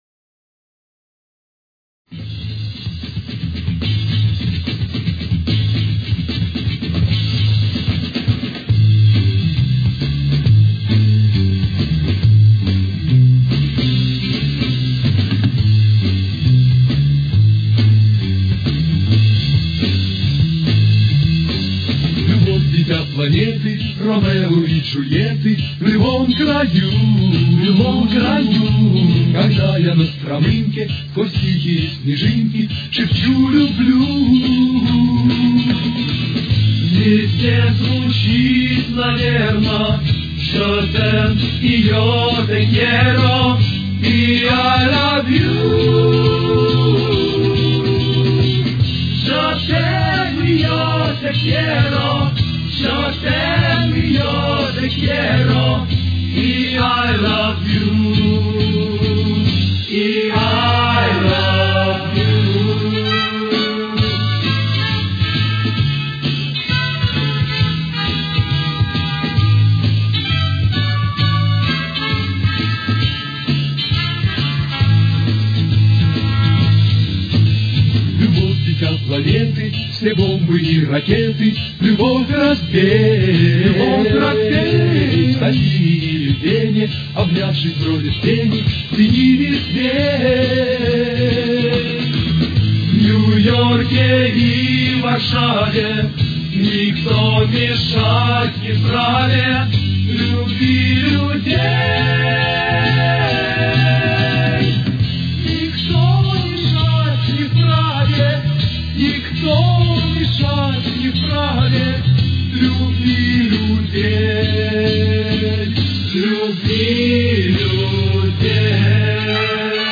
очень низким качеством (16 – 32 кБит/с)